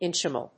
音節inch・meal 発音記号・読み方
/íntʃmìːl(米国英語)/